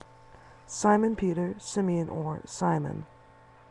The New Testament presents Peter's original name as Simon (/ˈsmən/
Pope_Peter_Pronunciation.ogg.mp3